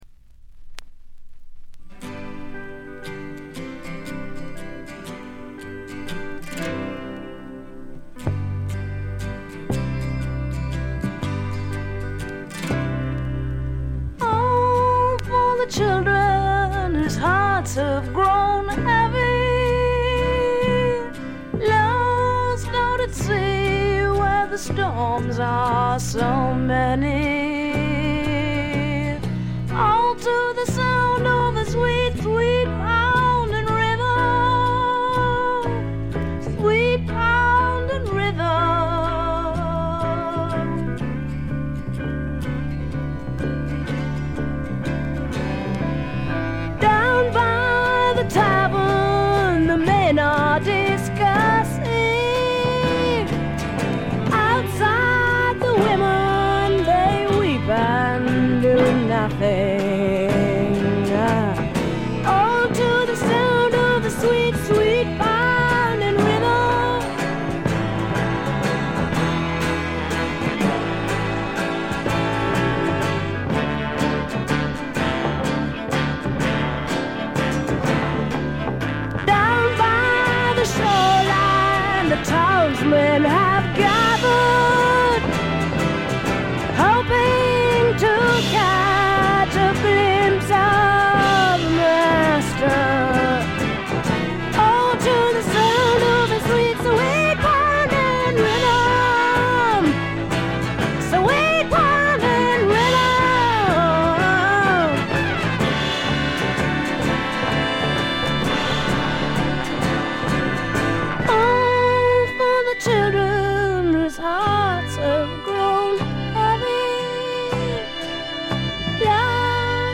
カナダ出身の女性シンガーソングライターが残したサイケ／アシッド・フォークの大傑作です。
試聴曲は現品からの取り込み音源です。